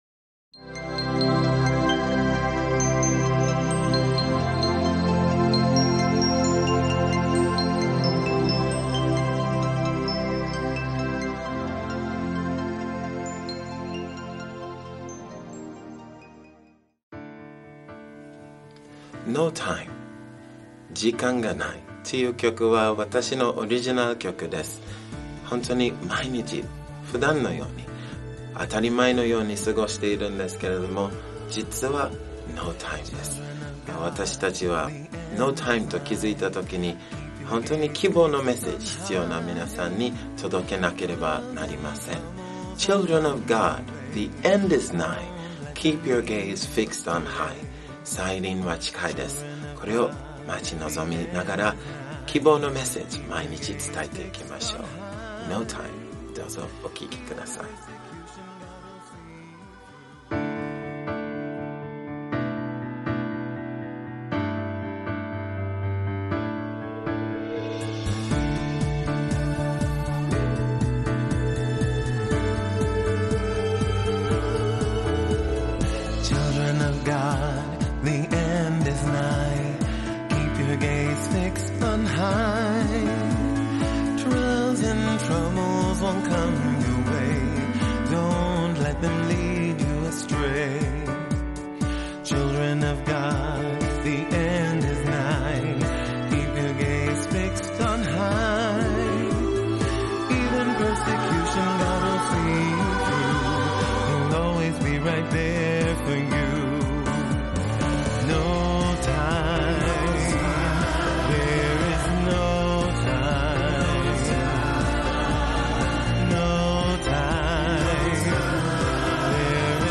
希望のゴスペルをどうぞ。
ゴスペルシンガー